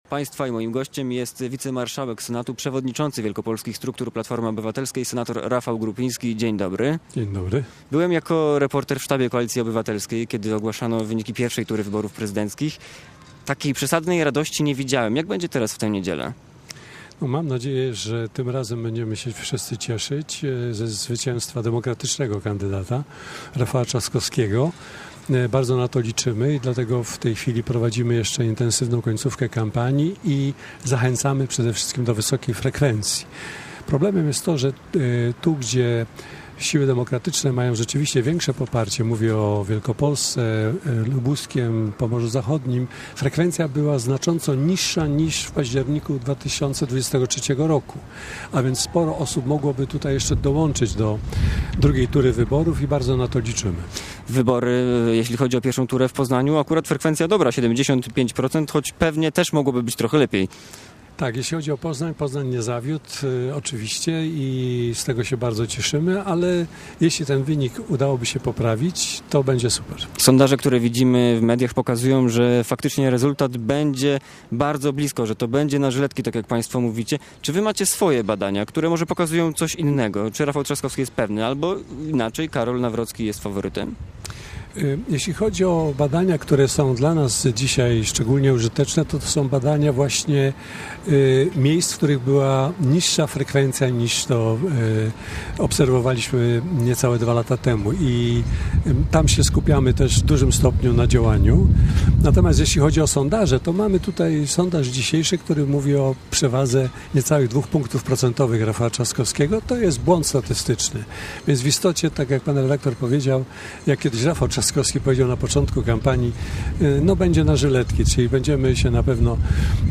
Popołudniowa rozmowa